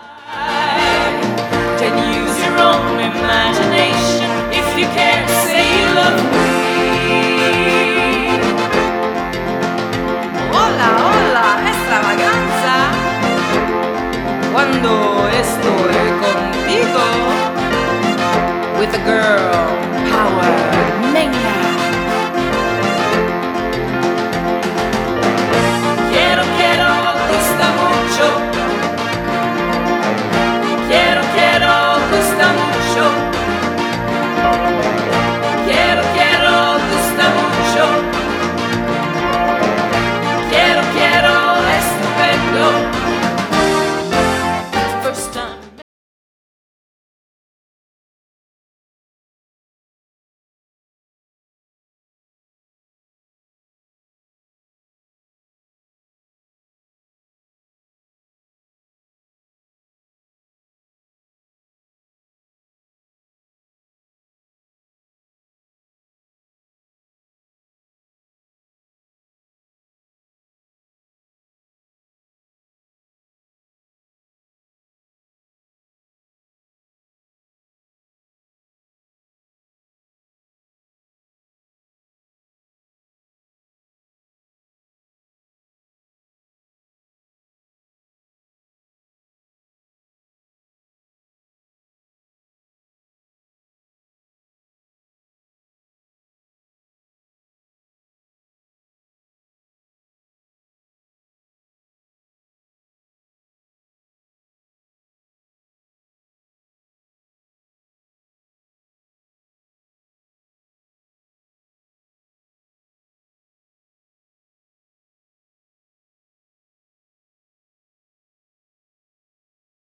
Enregistrement, Studio
Guitares: Électrique / Acoustique
Piano / Orgue
Choriste